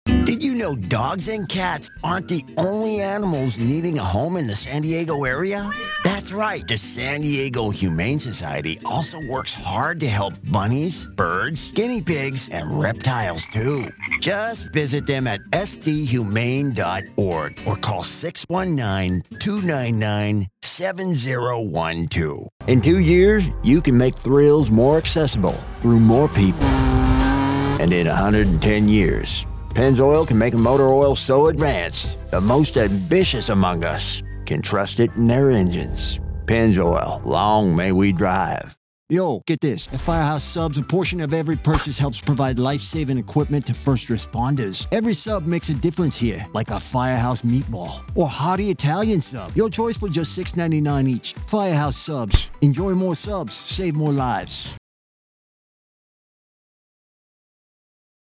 Male Voice Actor | Confident, Trustworthy, Conversational | Commercial & Corporate Specialist
CHARACTERS & ACCENTS
American male, Southern, aged, midwestern, New England Hybrid